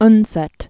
(nsĕt), Sigrid 1882-1949.